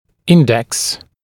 [‘ɪndeks] мн. [‘ɪndɪsiːz][‘индэкс] мн.